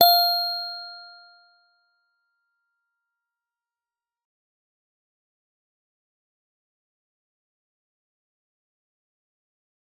G_Musicbox-F5-f.wav